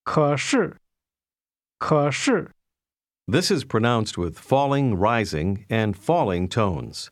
The Pimsleur narrator repeatedly draws our attention to patterns we don’t actually hear. Listen to the discrepancy between the pronunciation and the description of the Mandarin word for ‘but’, keshi, whose first syllable has Tone 3 in its default (low) form, followed by a syllable with Tone 4 (fall):